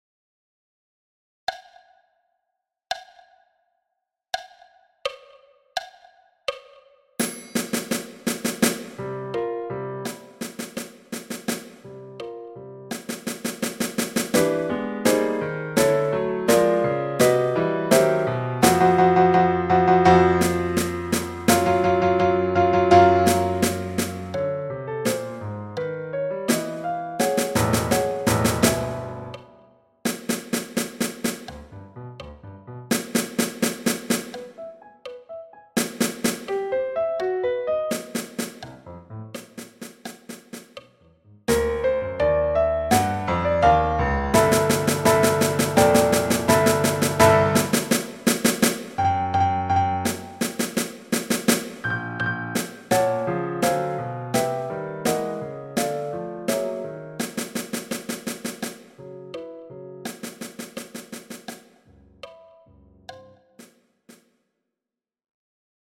PRELUDE-ET-ALLEGRO-a-84-bpm.mp3